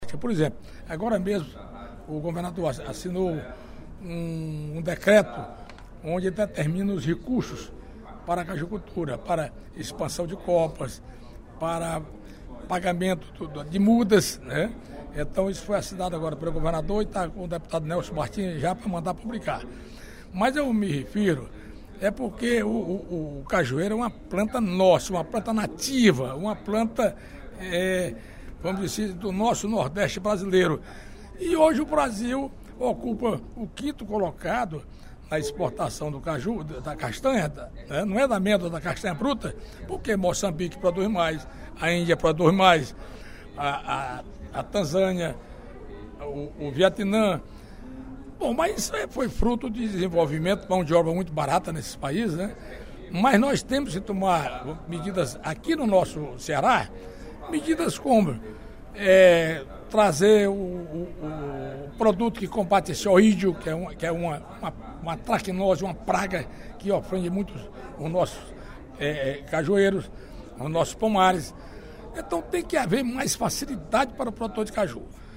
No primeiro expediente da sessão plenária desta terça-feira (12/11) da Assembleia Legislativa, o deputado Manoel Duca (Pros) destacou o Dia do Caju e a importância de incentivar a produção e exportação da fruta.